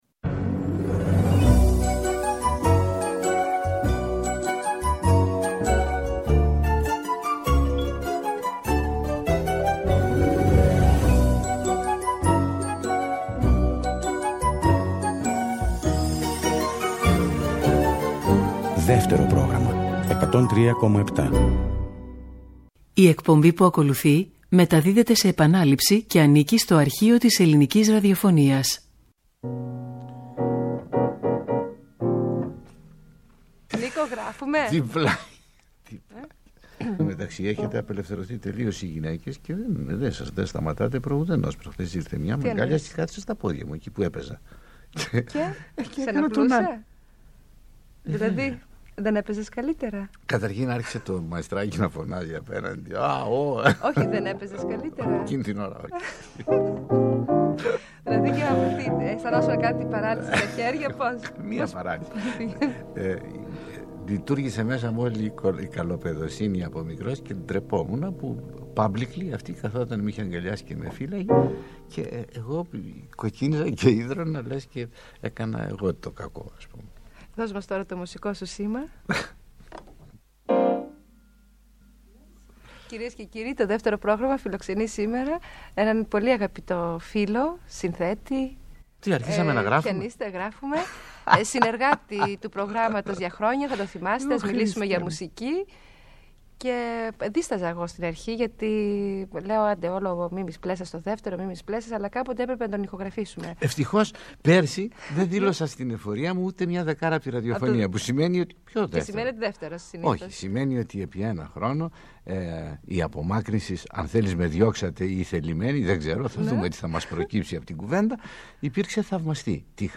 Από το Αρχείο της Ελληνικής Ραδιοφωνίας